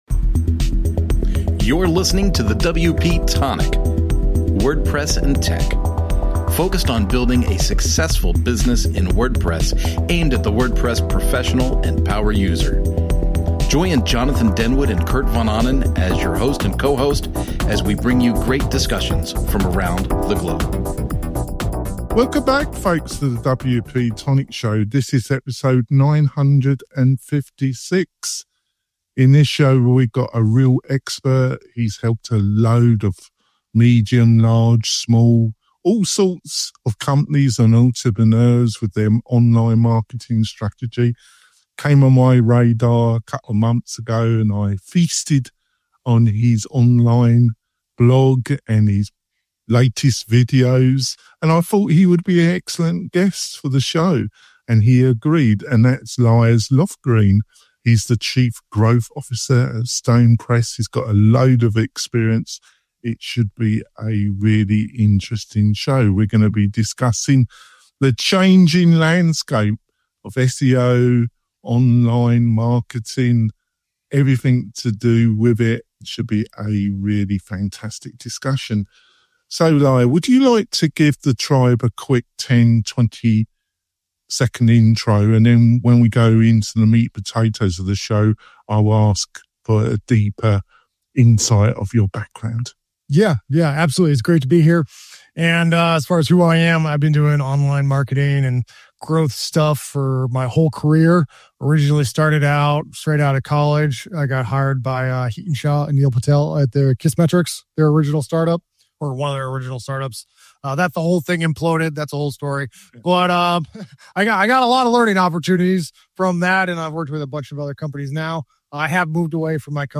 1 #926 - WP-Tonic Show: A Hosting Company's Journey Into The World of Marketing Automation 1:03:07 Play Pause 18h ago 1:03:07 Play Pause Play later Play later Lists Like Liked 1:03:07 A hosting company's journey to discover innovative tactics that fueled growth and improved client relations. In this informative interview, we take you behind the scenes of a hosting company's venture into marketing automation.